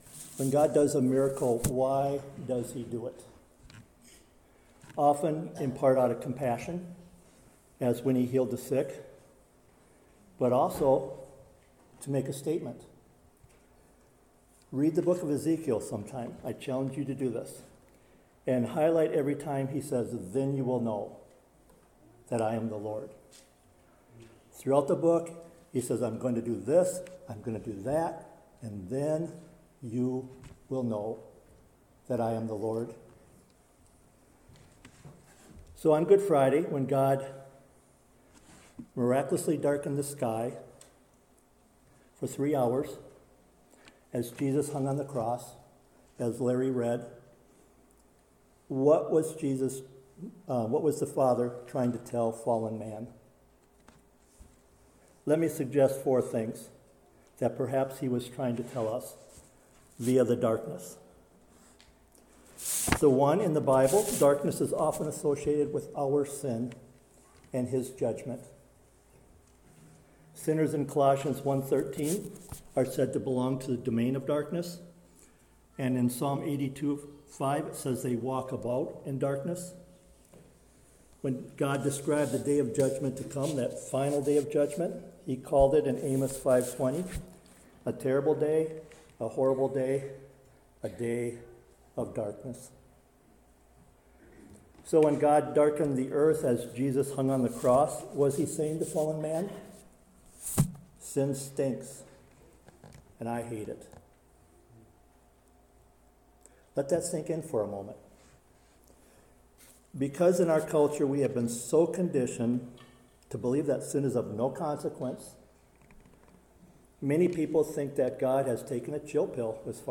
Sermons | Westview Primitive Methodist Church
This was during our community Good Friday service.